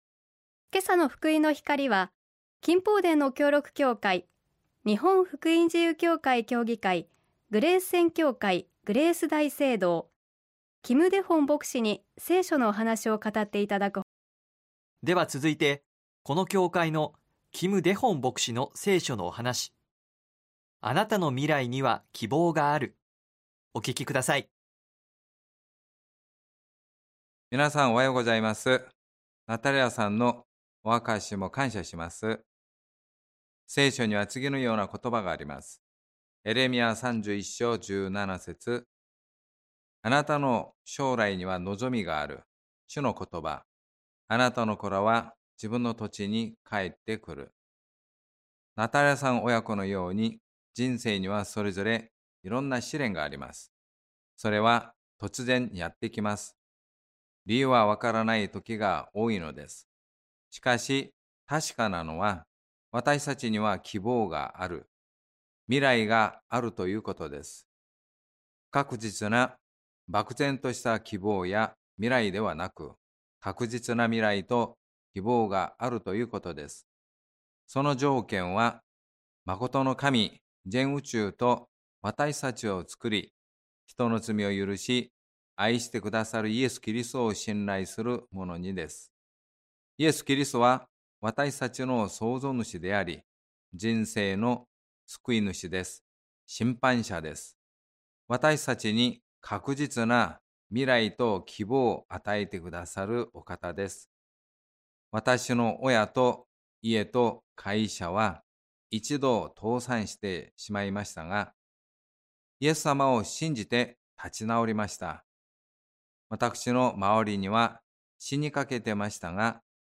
聖書のお話「あなたの未来には希望がある」
信仰体験談